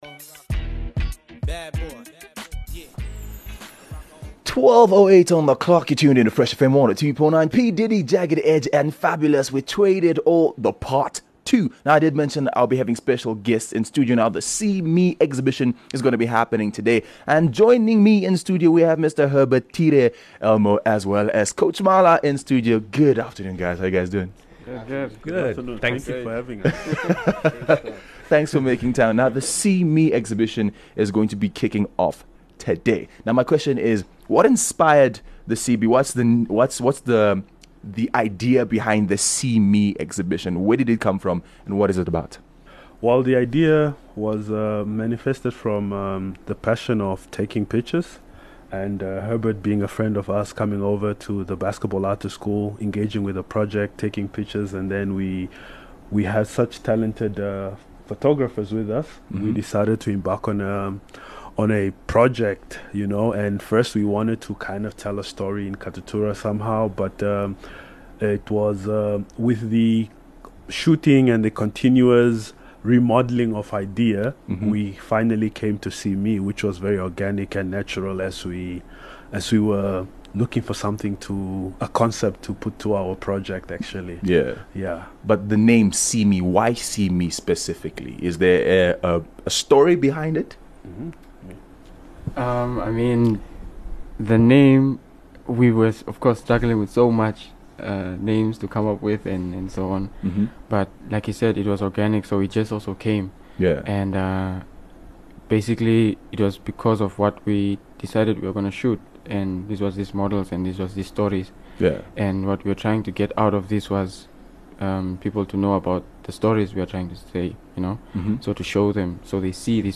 join us in studio to chat about the photography based exhibition titled, see me.